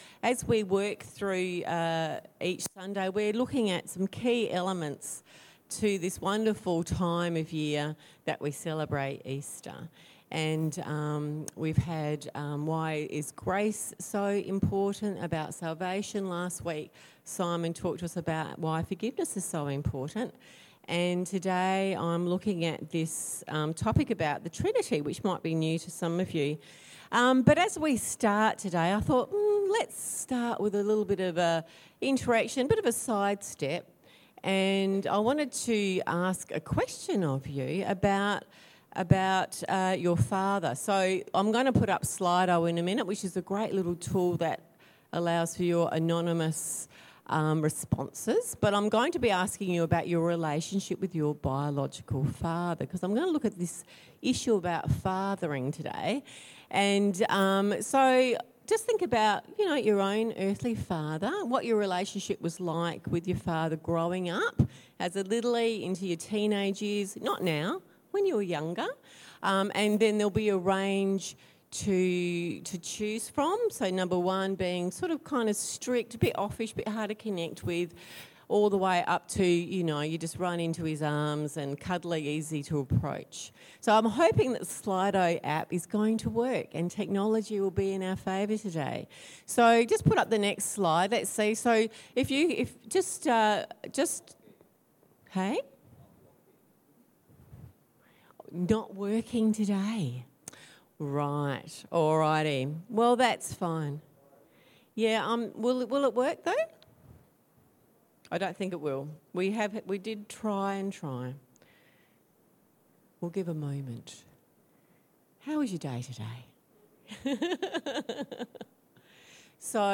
Sermons | Marion Vineyard Christian Fellowship